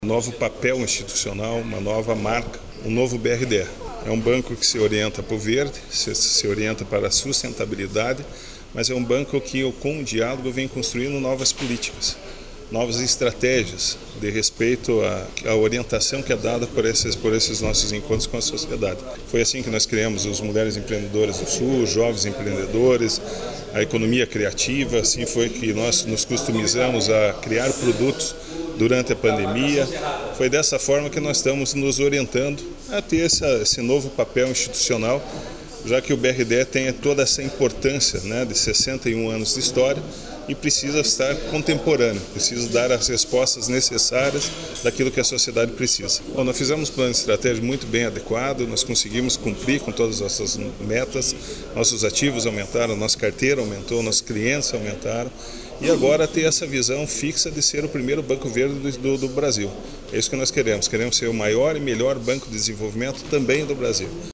Sonora com o presidente do BRDE, Wilson Bley, sobre o foco em ser a maior instituição de fomento do País